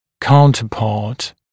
[‘kauntəpɑːt][‘каунтэпа:т]аналог; зуб-антагонист; зуб-аналог; зуб, занимающий аналогичное положение в противоположной зубной дуге